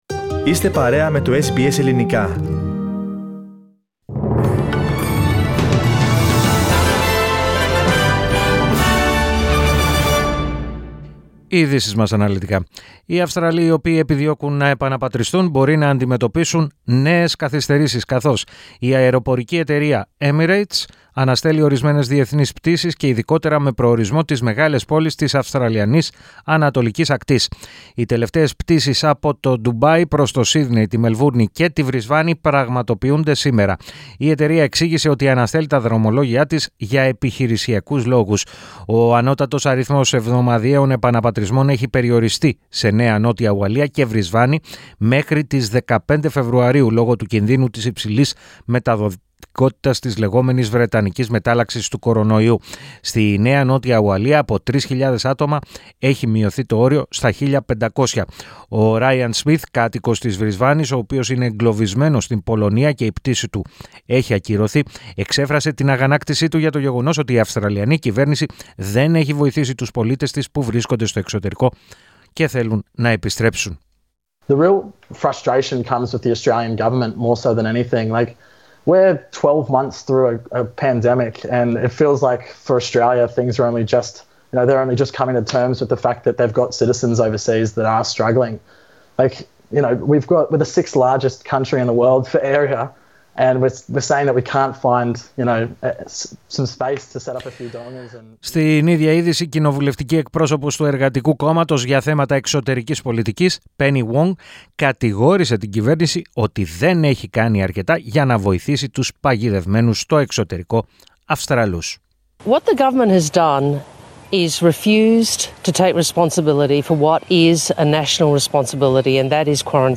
Ειδήσεις 16.01.21